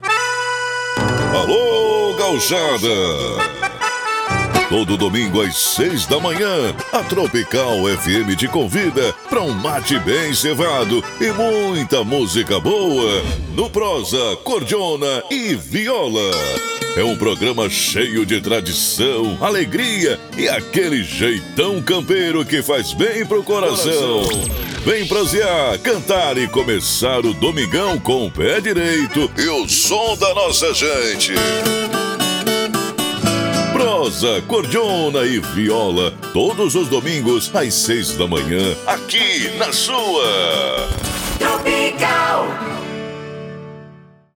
Chamada Prosa, Cordiona e Viola: